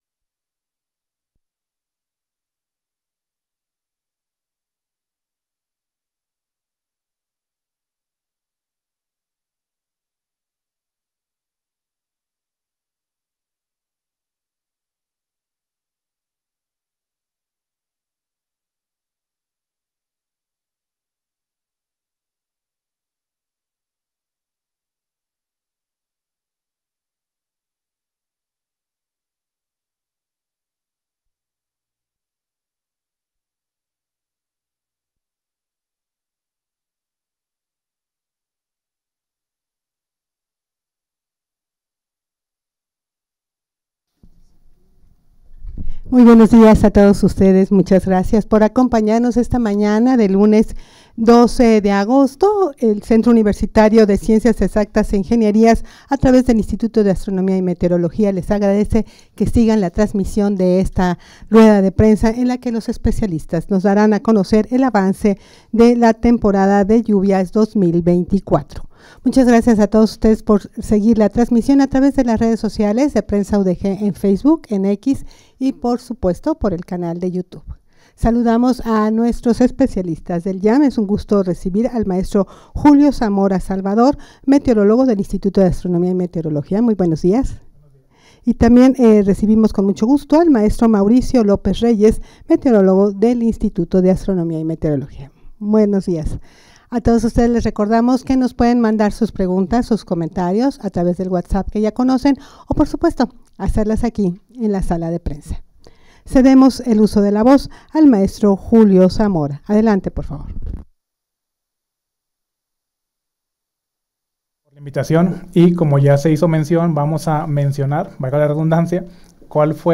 rueda-de-prensa-avance-del-temporal-de-lluvias.mp3